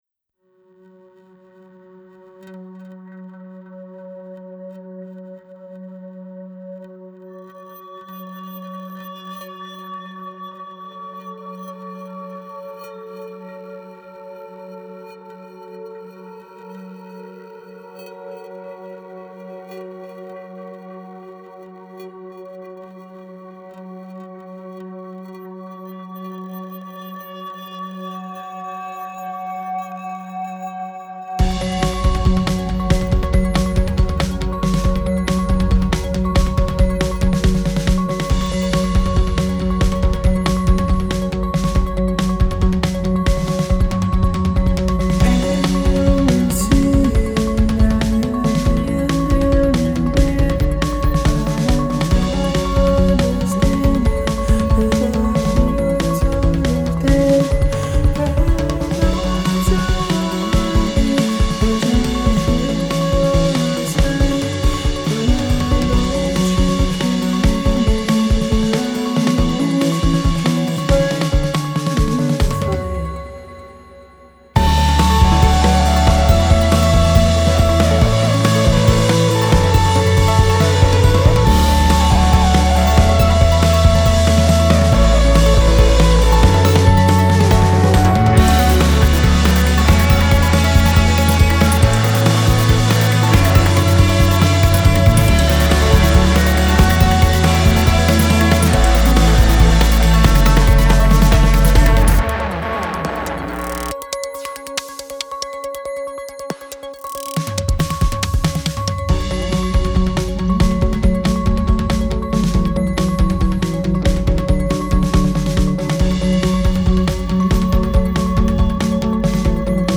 It’s a mix of electronic and indie rock.
So I suppose electronic indie rock is the best description.
I think it has a good mix of musical elements in it.